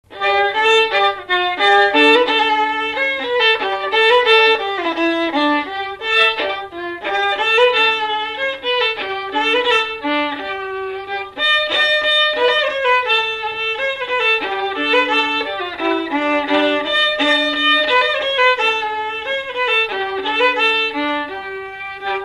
Mémoires et Patrimoines vivants - RaddO est une base de données d'archives iconographiques et sonores.
Air de violon
Pièce musicale inédite